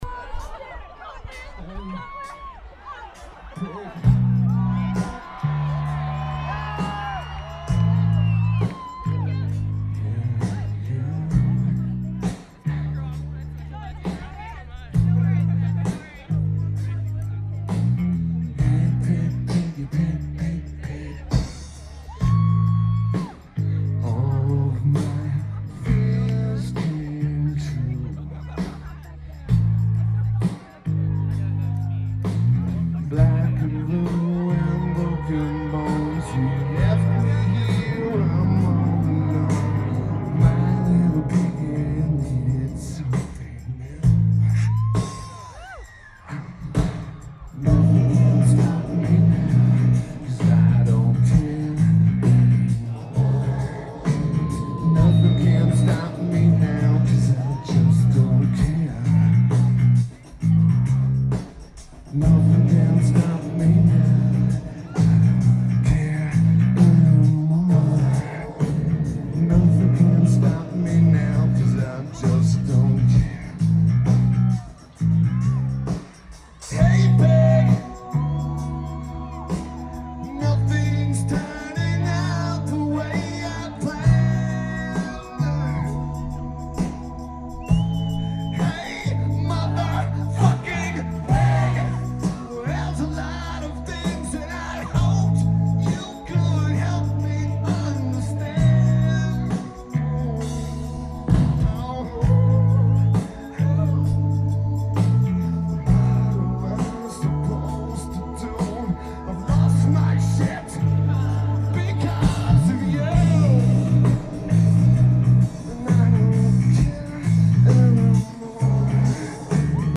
Coachella Music & Arts Festival